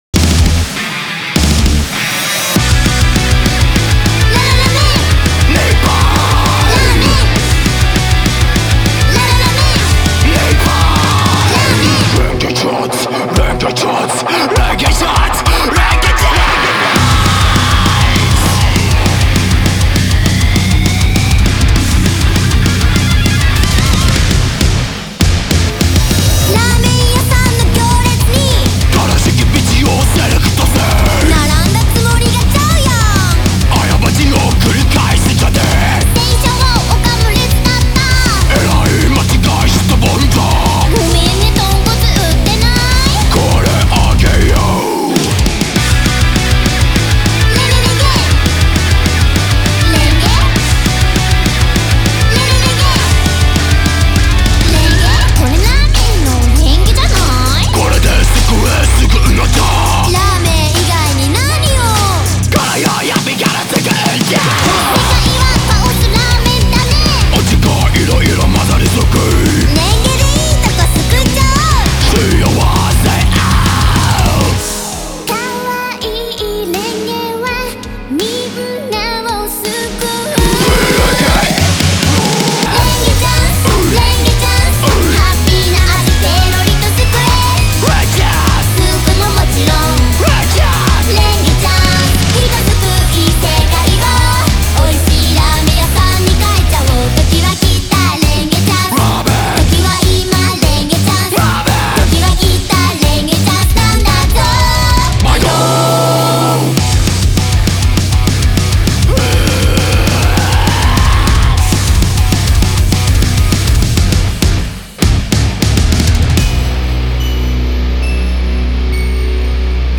BPM82-200
Audio QualityMusic Cut